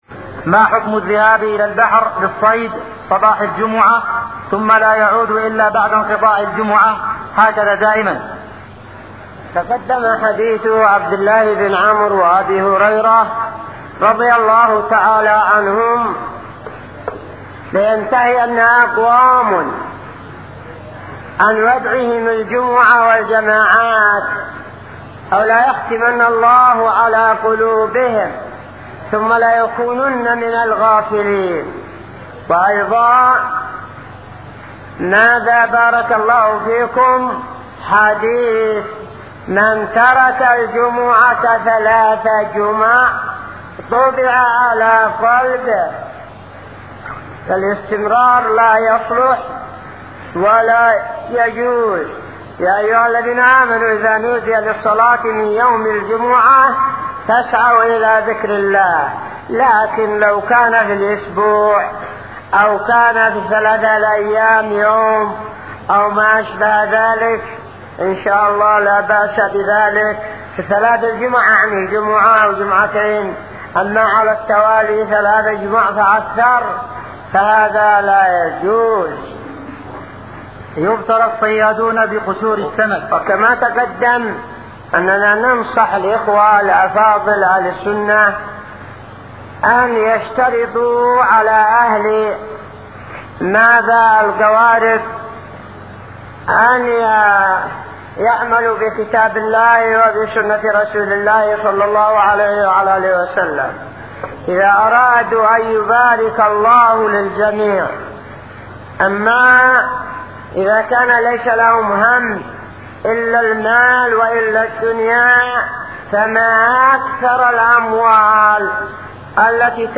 -------------- من شريط : ( أسئلة شباب قصيعر بحضرموت )